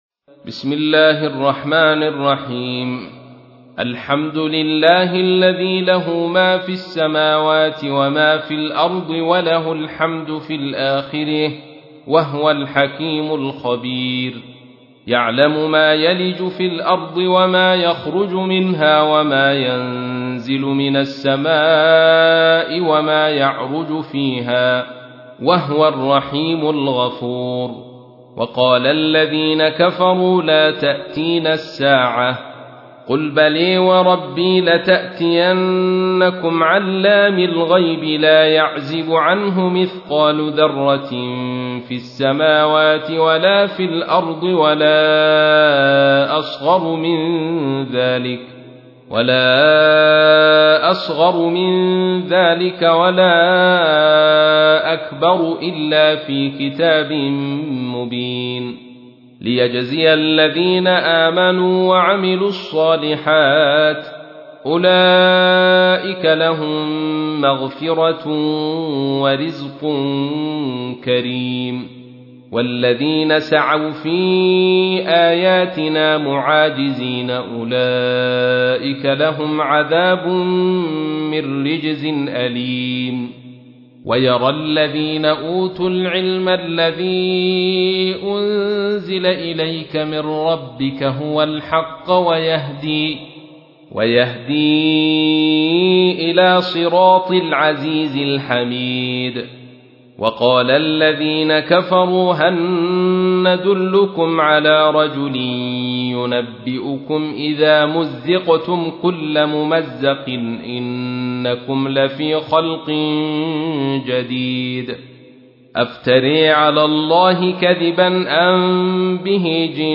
تحميل : 34. سورة سبأ / القارئ عبد الرشيد صوفي / القرآن الكريم / موقع يا حسين